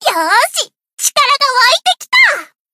BA_V_Izumi_Swimsuit_Battle_Buffed_1.ogg